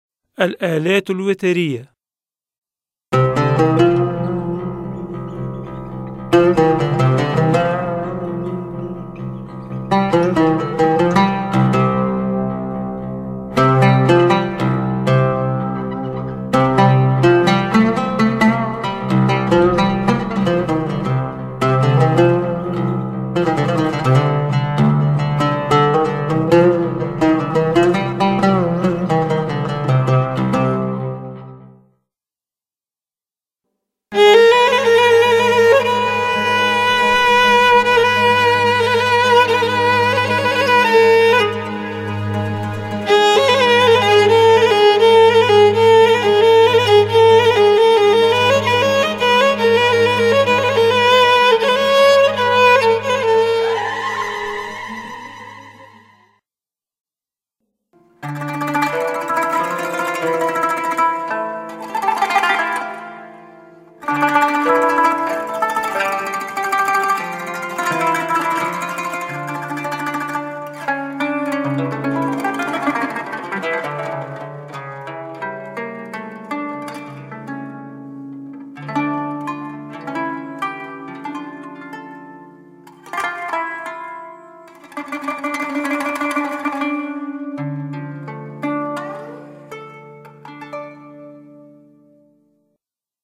تربية-موسيقية-الالات-الوترية.mp3